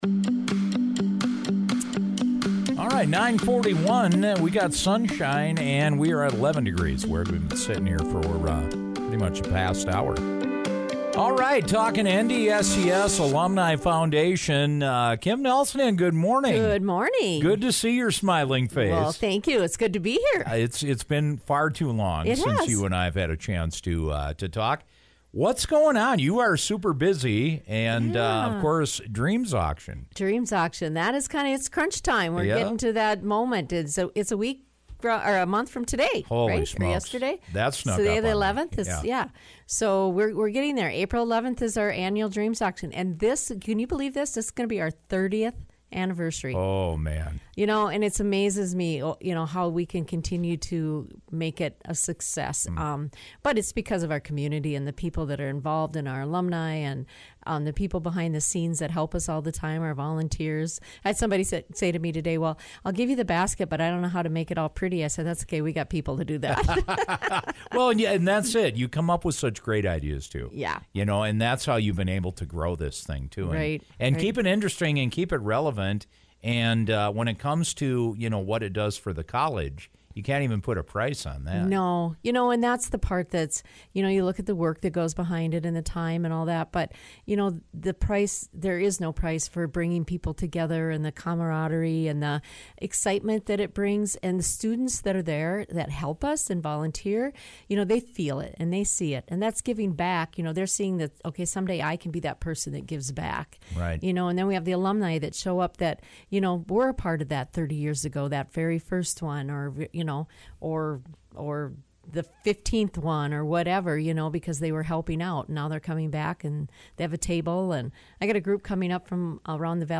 dropped by the Morning Show today to talk about this great event!